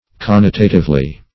Search Result for " connotatively" : The Collaborative International Dictionary of English v.0.48: Connotatively \Con*no"ta*tive*ly\, adv.
connotatively.mp3